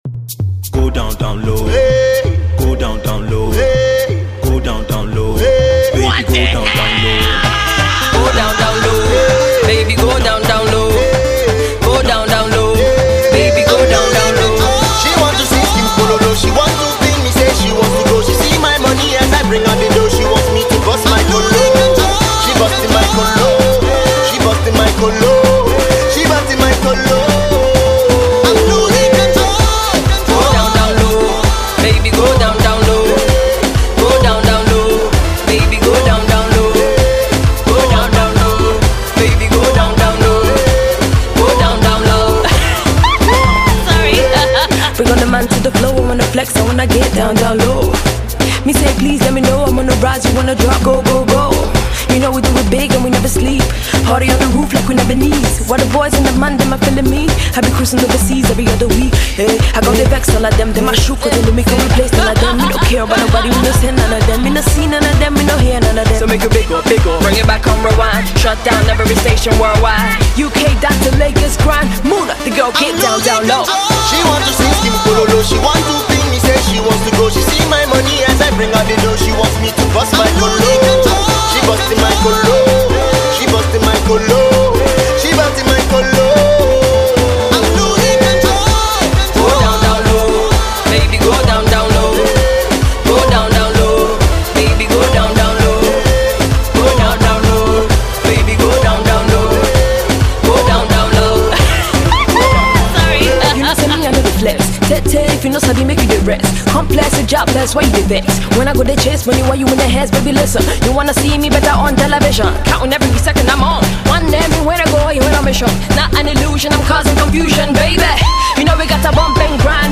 more uptempo dance track